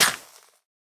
Minecraft Version Minecraft Version latest Latest Release | Latest Snapshot latest / assets / minecraft / sounds / block / suspicious_gravel / break1.ogg Compare With Compare With Latest Release | Latest Snapshot